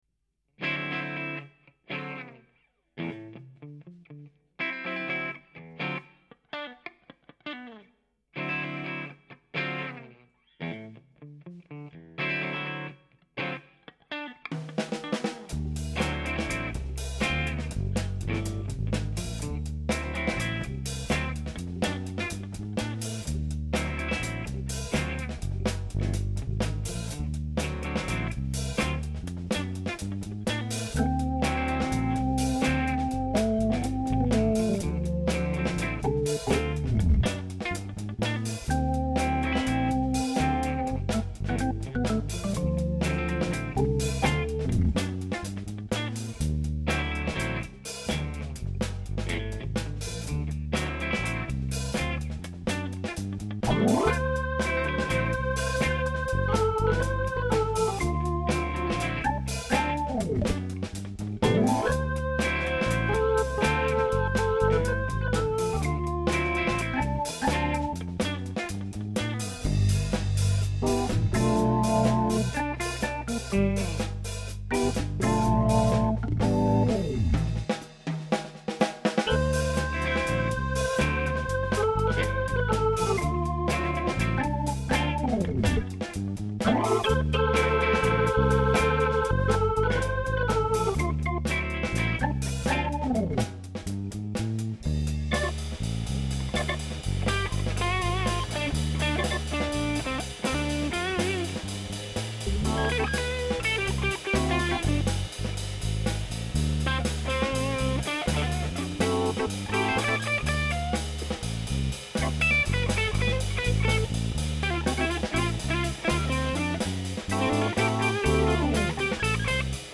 Schlagzeug, Perkussion, Tasteninstrumente
Gitarren, Bass